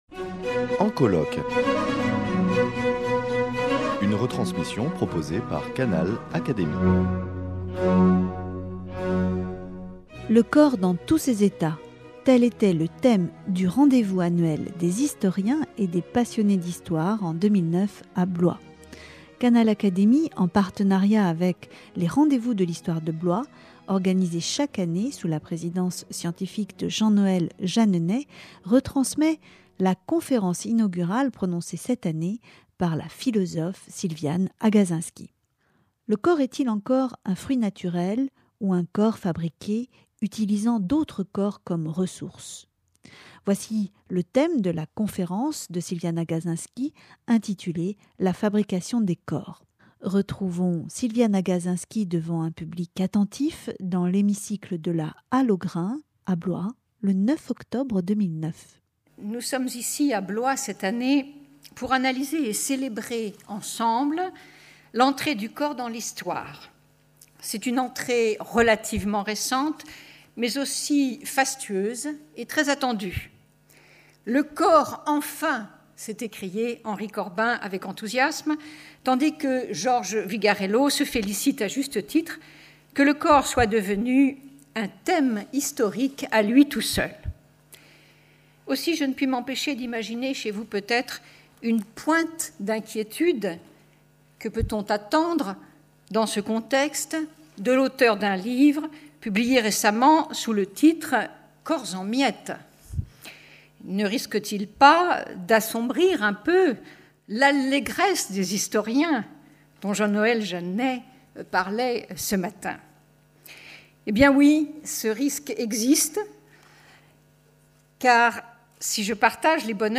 Voici la retransmission intégrale de la leçon inaugurale des Rendez-vous de l’Histoire de Blois 2009 que la philosophe a prononcée à la Halle aux grains, le 9 octobre 2009.